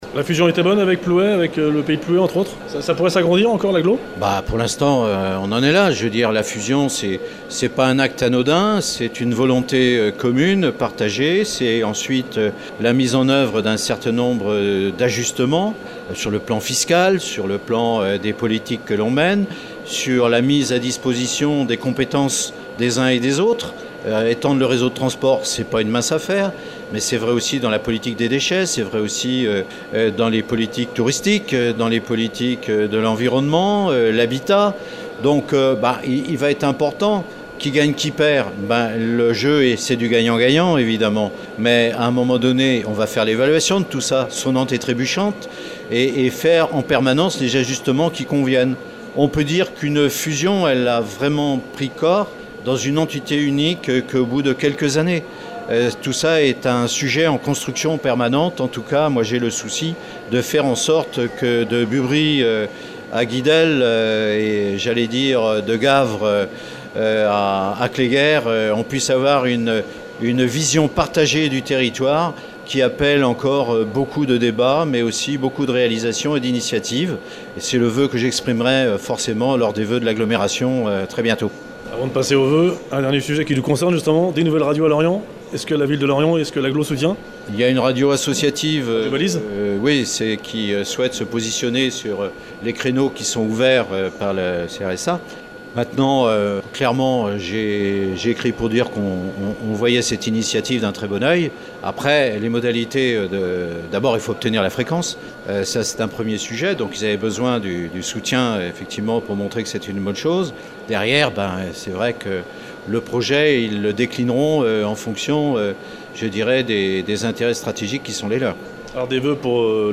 VOEUX 2016 pour LORIENT et son Agglomération – Interview Norbert Métairie
VOEUX 2016 pour LORIENT et son Agglomération – Interview Norbert Métairie – Maire de Lorient et Président de Lorient Agglomération qui évoque les chantiers et projets en cours sur le Pays de Lorient : Le Triskell et les Services de Bus, La Gare, Le Parc Jules Ferry, La Pelouse du Stade du Moustoirs, Les Studios MAPL et le futur Manège, La fusion intercommunale avec Plouay et son Pays, les nouvelles Radios sur la FM…